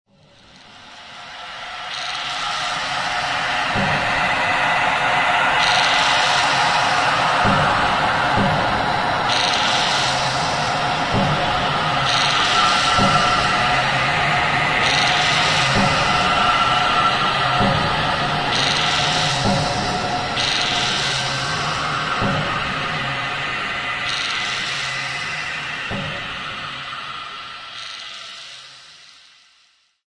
Essentially what I did was I took the original 1.5 second sample and twisted it until it was completely unrecognizable. Like a strange lost transmission from space.